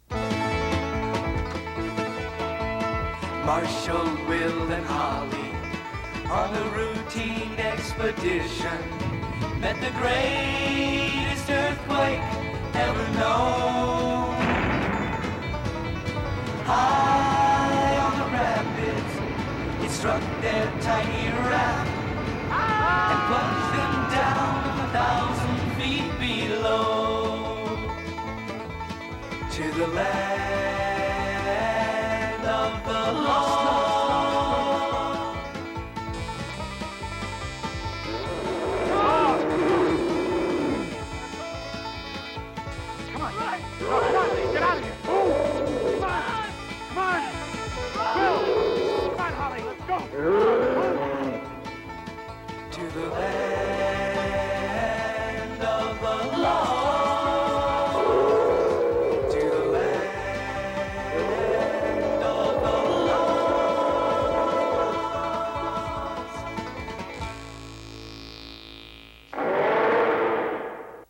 theme song in later episodes.